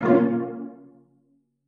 TS Pizzicato.wav